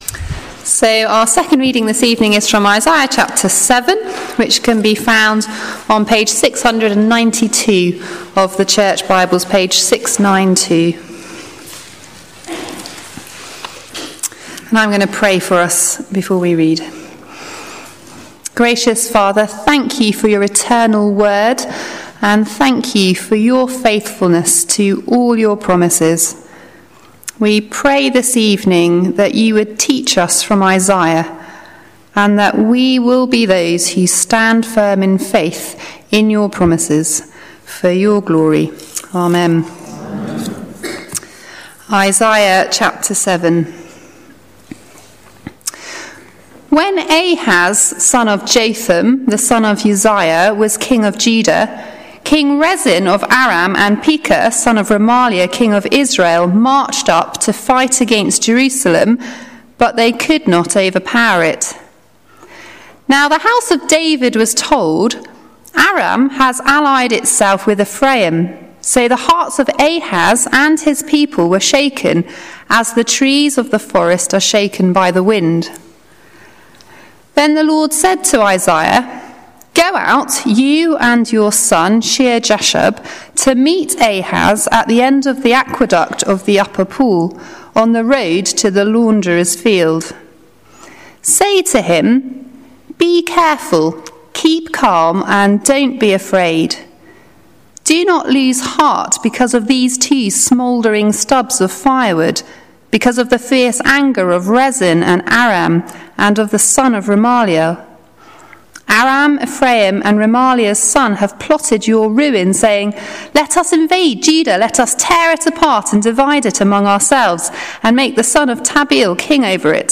Sermons Archive - Page 63 of 188 - All Saints Preston
John 1:1-5 – All Age Service